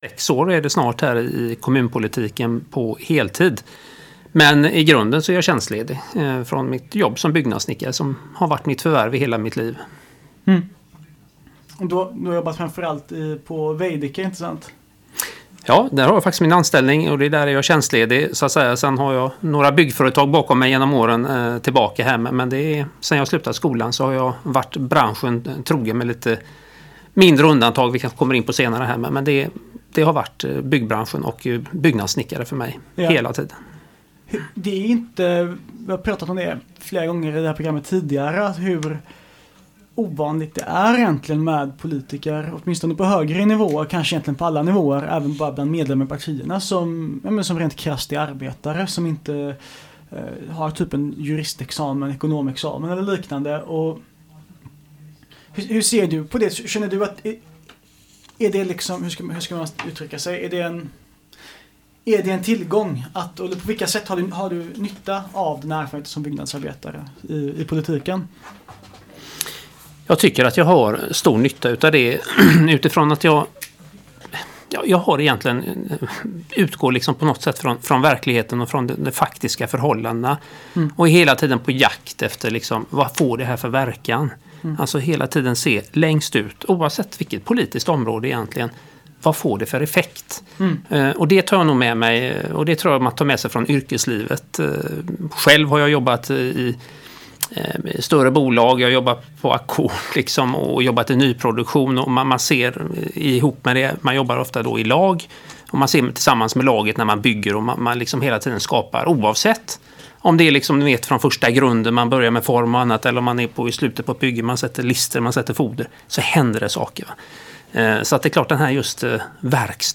Av tekniska skäl är inte första minuterna av programmet med. Musiken är bortklippt av upphovsrättsliga skäl.